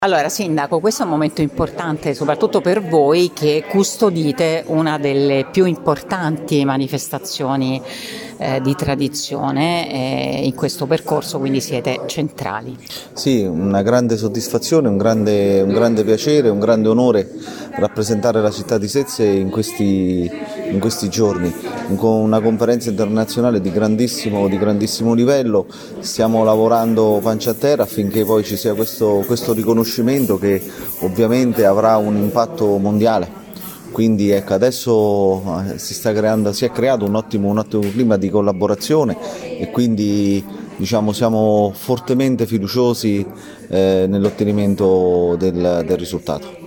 Soddisfazione è stata espressa dal primo cittadino di Sezze